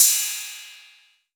808CY_4_Orig.wav